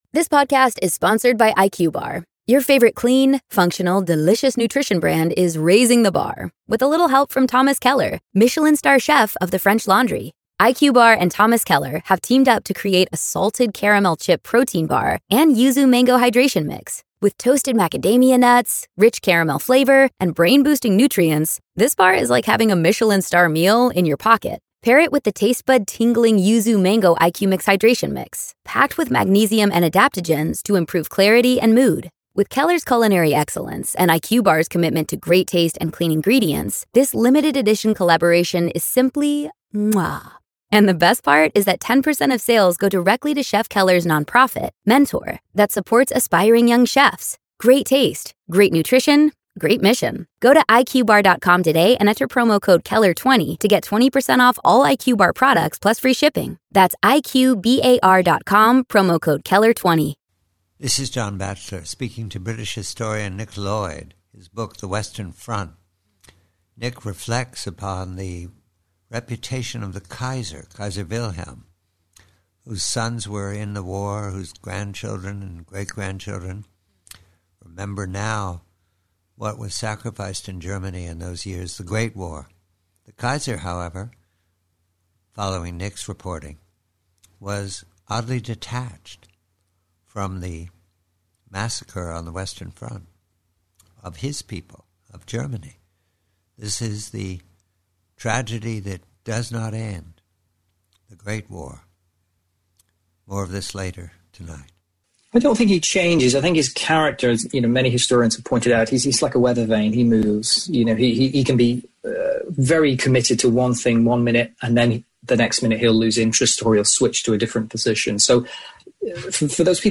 PREVIEW: GREAT WAR: Kaiser Wilhelm: Conversation